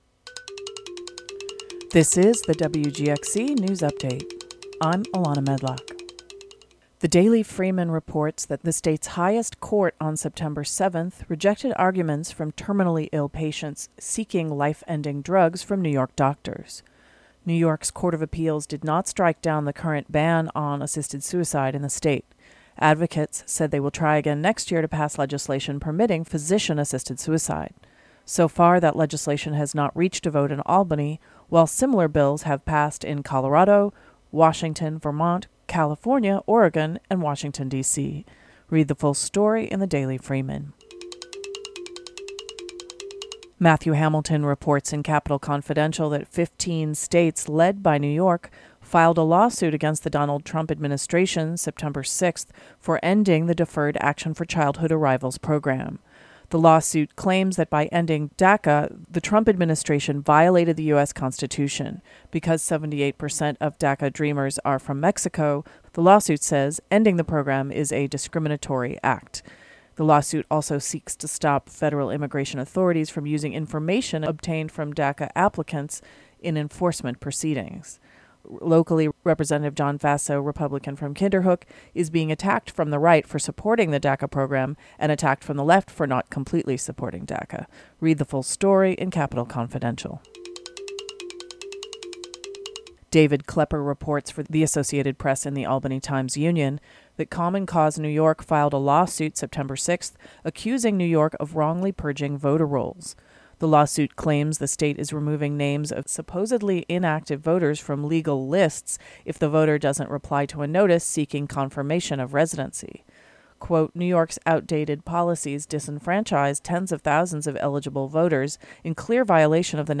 DOWNLOAD or play the audio version of the local news update for Thursday, Sept. 7 (4:07).